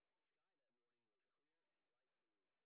sp10_exhibition_snr10.wav